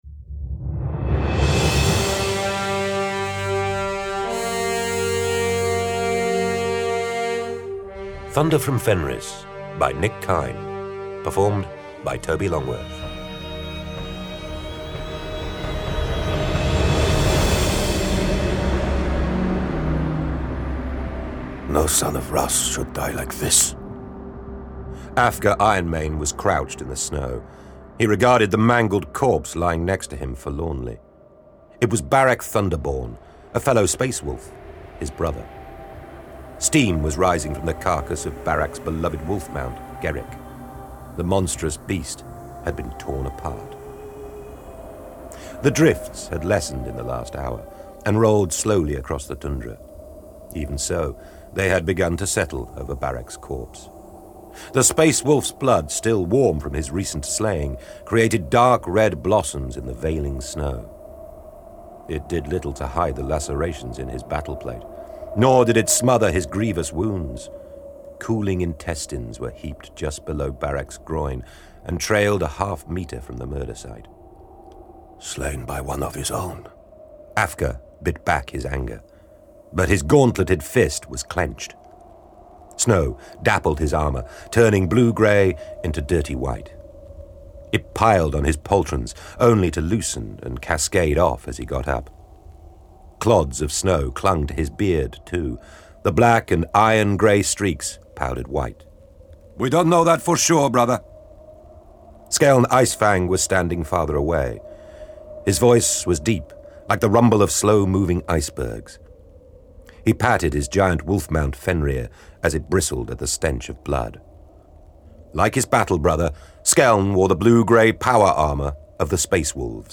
Index of /Games/MothTrove/Black Library/Warhammer 40,000/Audiobooks/Thunder from Fenris